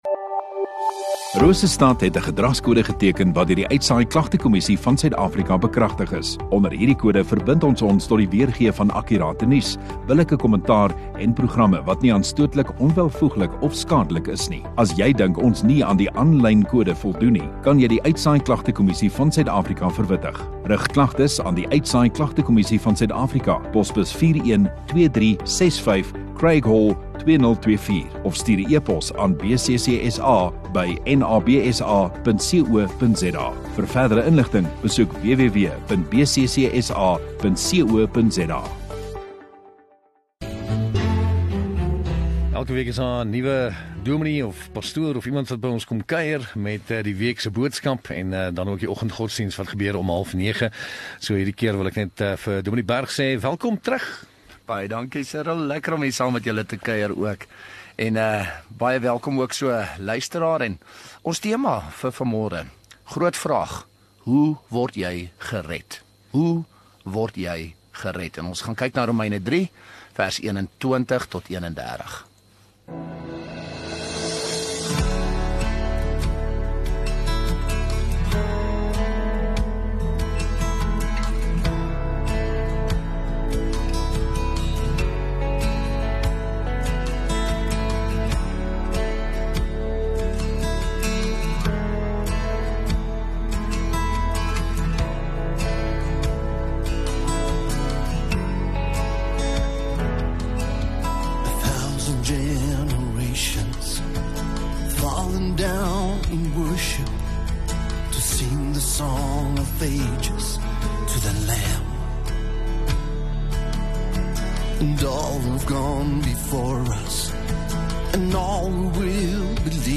3 Jun Maandag Oggenddiens